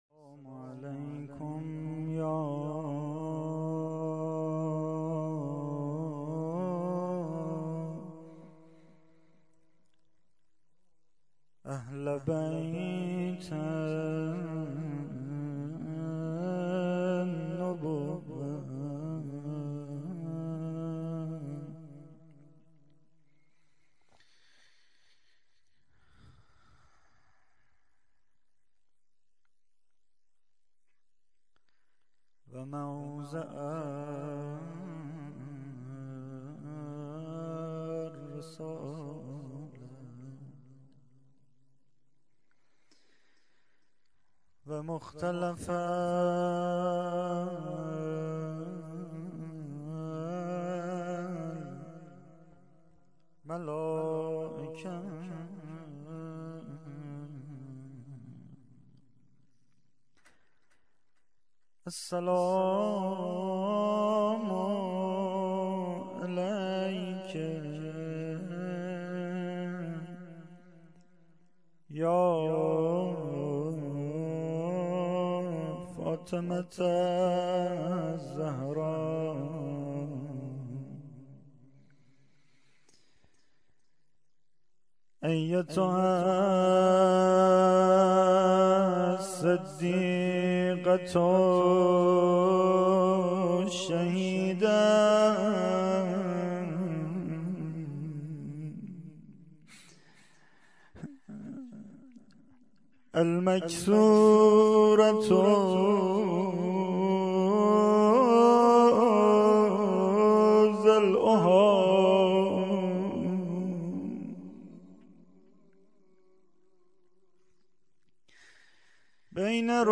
rozeh.mp3